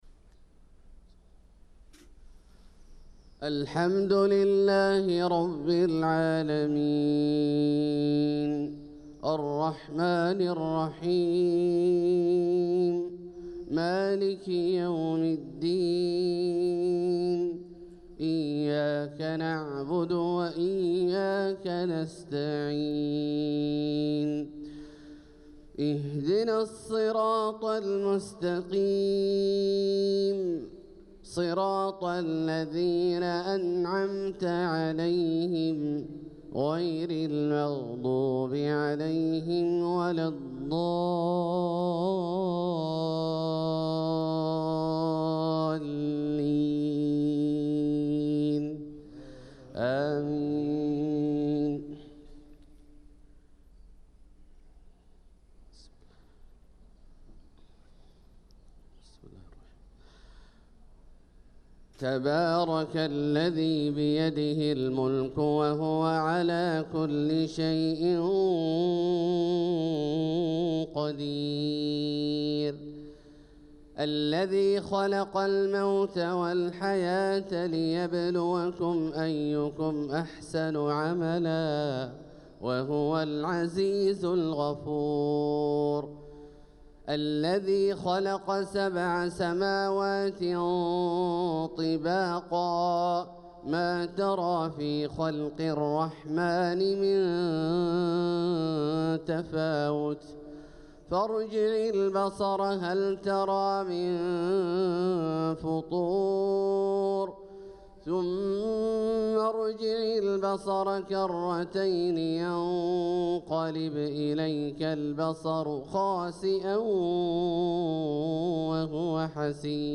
صلاة الفجر للقارئ عبدالله الجهني 25 صفر 1446 هـ
تِلَاوَات الْحَرَمَيْن .